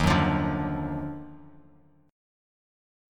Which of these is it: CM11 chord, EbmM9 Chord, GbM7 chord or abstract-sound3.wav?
EbmM9 Chord